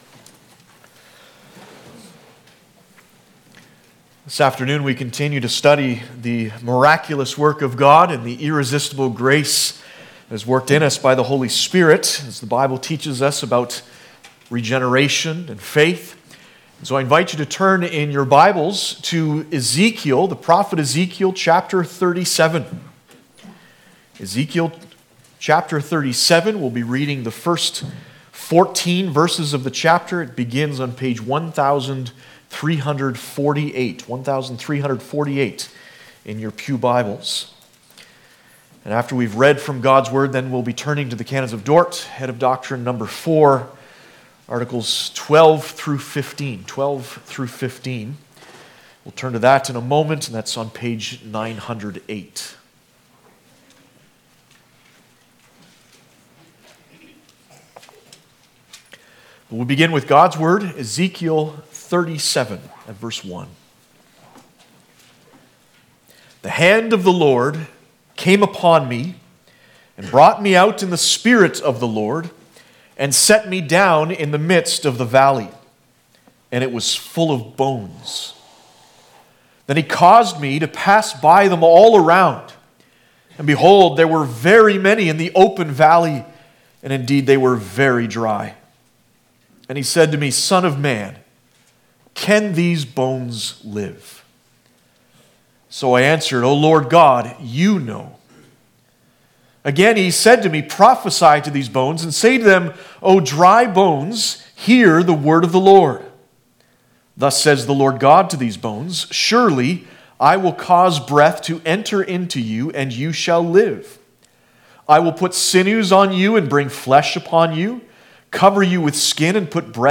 Passage: Ezekiel 37:1-14 Service Type: Sunday Afternoon